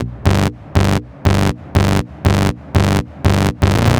TSNRG2 Bassline 016.wav